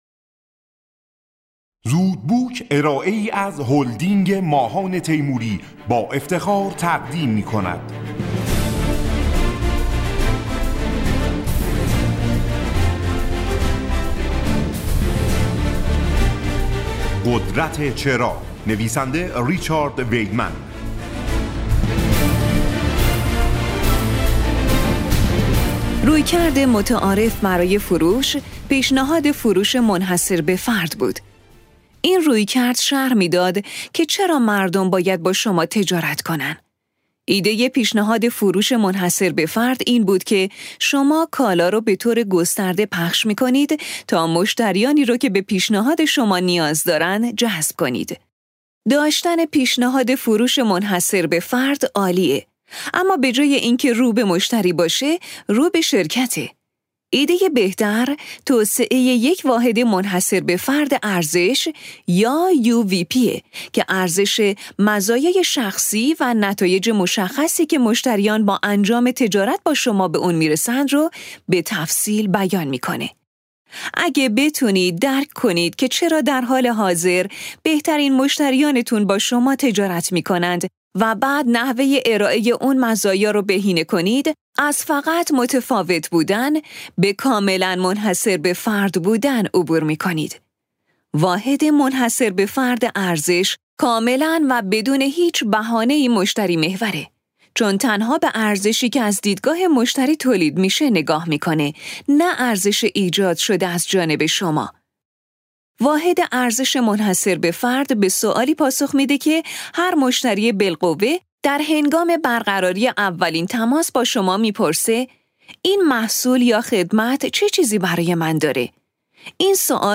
خلاصه کتاب صوتی قدرت چرا